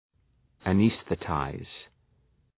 Προφορά
{ə’nesɵı,taız} (Ρήμα) ● αναισθητοποιώ